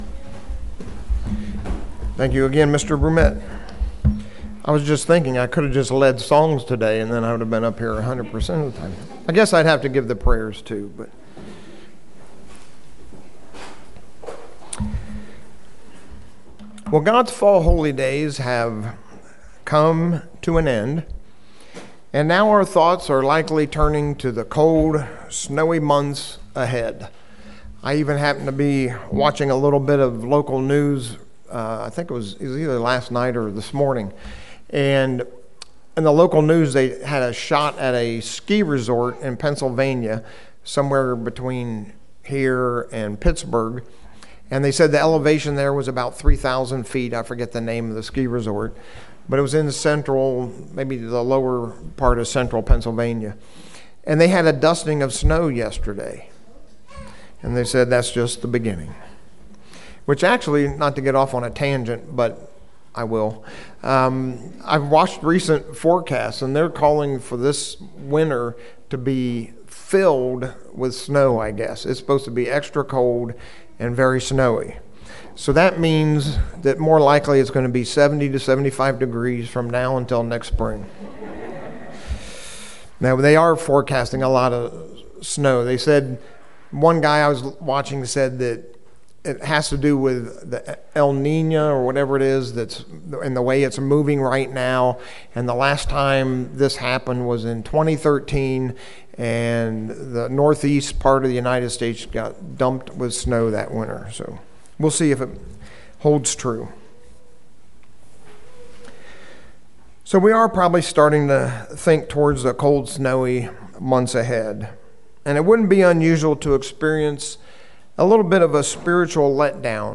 As we leave behind the fall Holy Days and approach the coldness of winter, we are called to stay fervent in our spiritual walk towards the Kingdom of God. This sermon looks at three key areas to help us stay motivated and watchful.